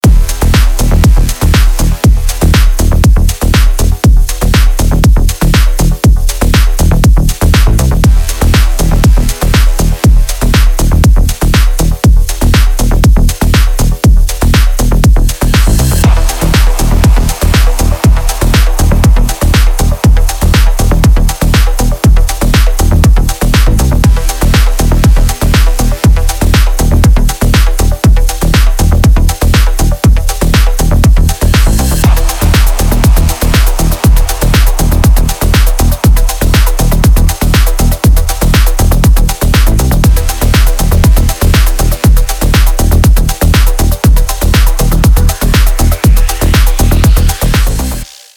Клубные обрезки без слов
Клубные рингтоны